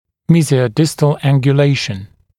[ˌmiːzɪəu’dɪstl ˌæŋgju’leɪʃən][ˌми:зиоу’дистл ˌэнгйу’лэйшэн]мезиодистальная ангуляция, мезиодистальный наклон